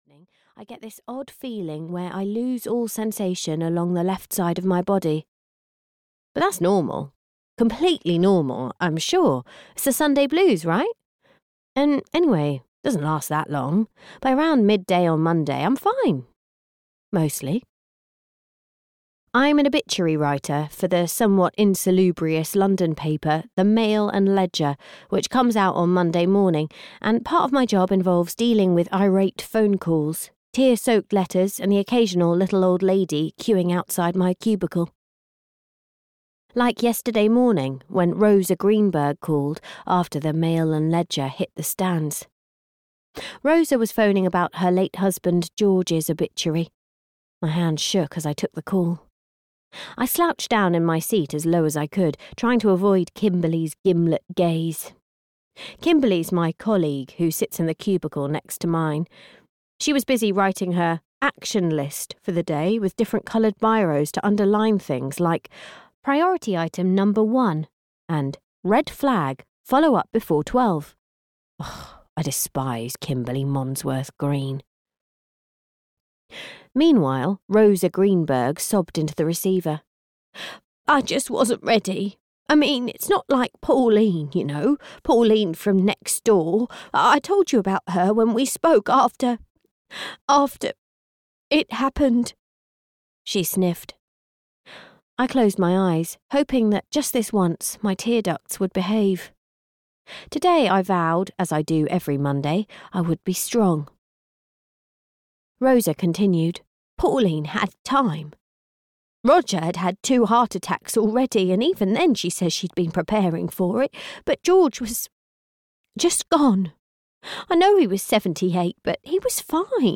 The Summer Escape (EN) audiokniha
Ukázka z knihy